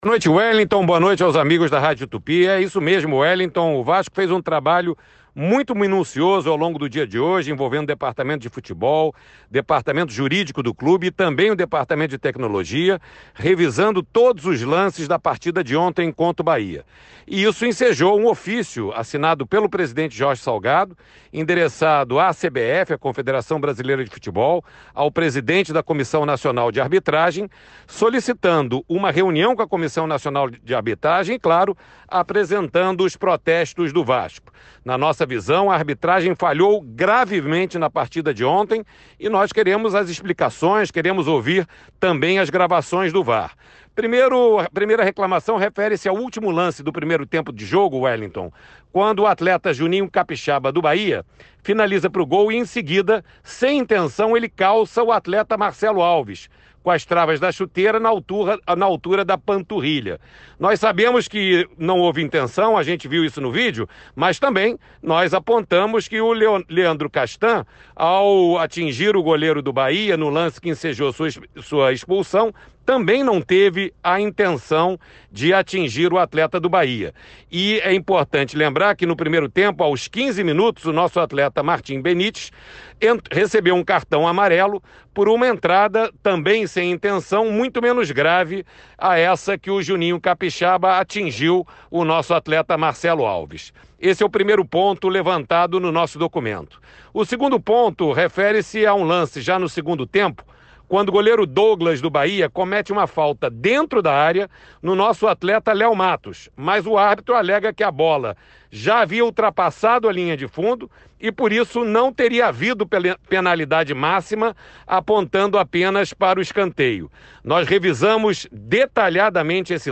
e falou no Giro Esportivo da Tupi sobre os protestos vascaínos.